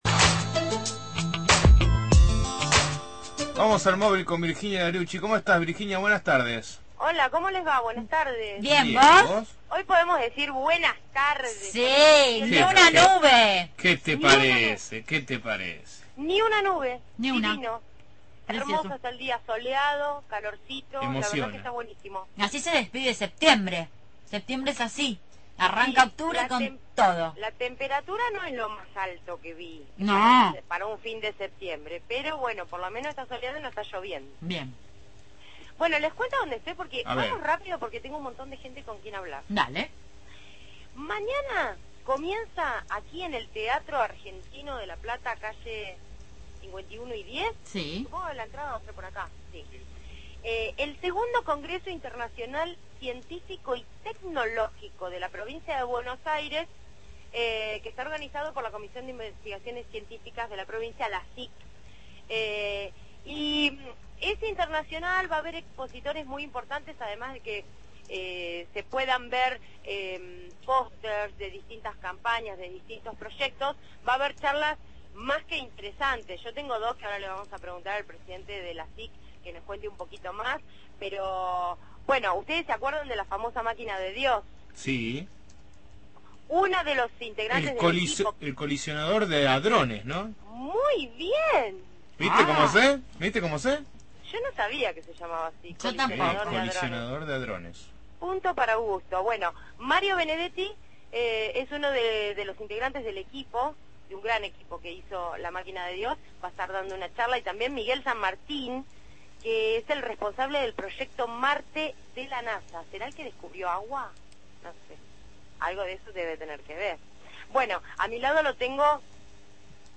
MÓVIL/ Exposición de vehículos amigables de protolitio – Radio Universidad